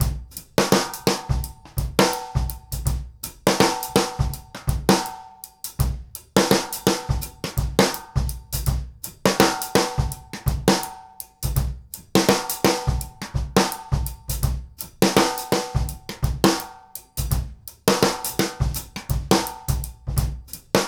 GROOVE 130CR.wav